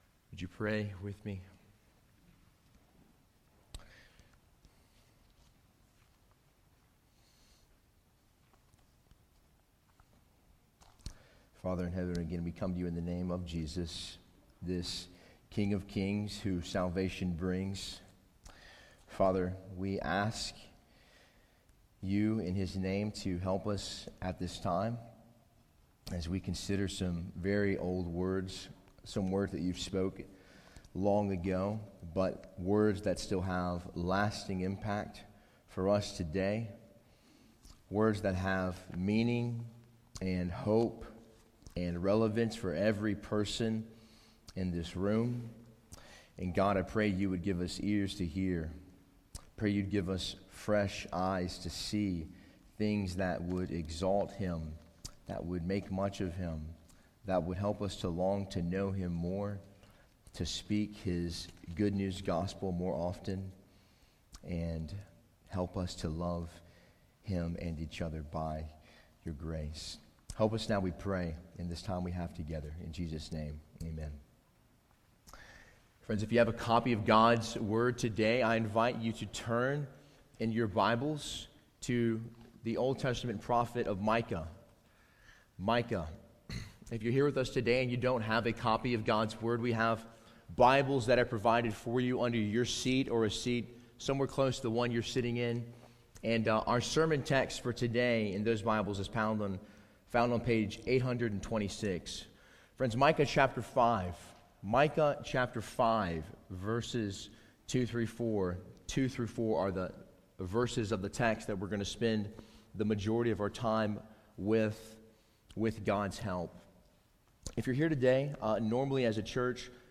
Sermon Audio 2019 December 15